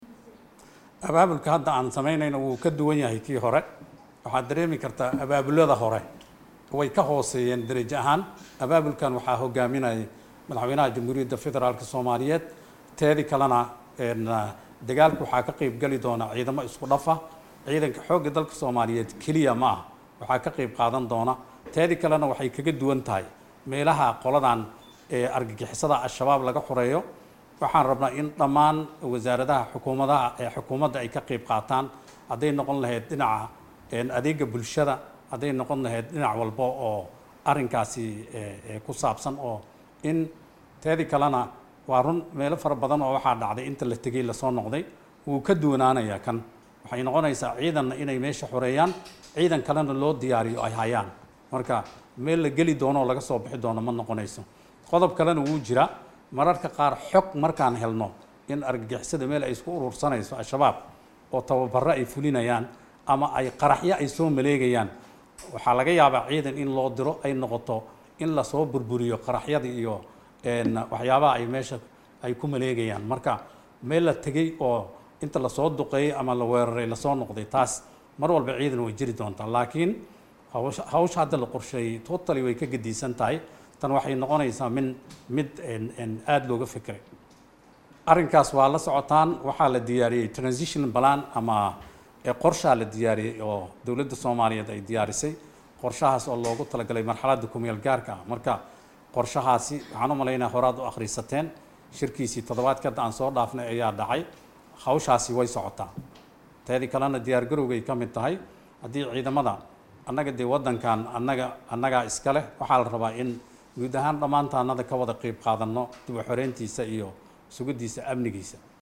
Wasiirka Wasaaradda Gaashaandhiga xukumadda Federaalka Soomaaliya mudane Maxamed Mursal shiikh C/raxmaan wareysi uusiiyay Warbaahinta Qaranka Soomaaliya ayaa sheegay in dhawaan la bilaabayo Dagaal ka dhan ah maleeshiyaadka kaasooo Dalka looga saarayo argagaxusada naba diyo nolal diidka ah ee Al shabaab.